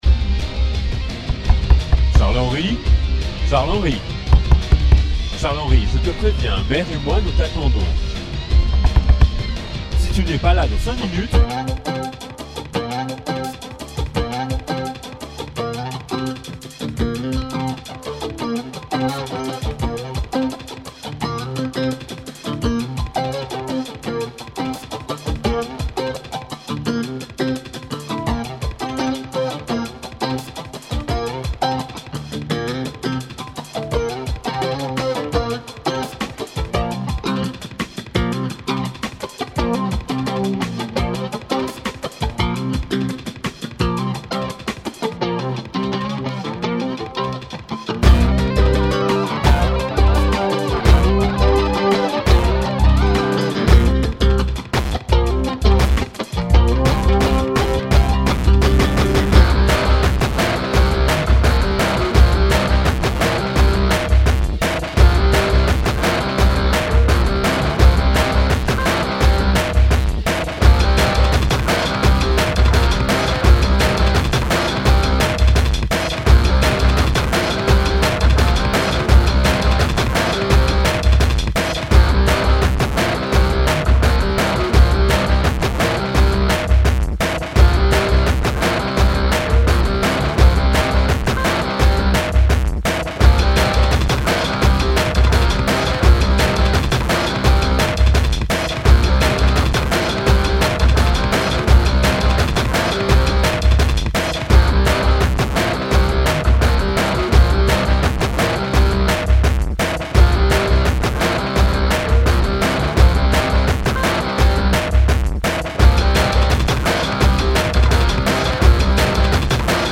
C'est de l'électro.
Une autre approche de la guitare fretless en chorus.
desolé mais mettre une disto sur une fretless je vois pas l interet , le sample sonne comme une frettée avec des dissonance en plus alors bon a quoi ca sert ?
bin a avoir les micro dissonances justement…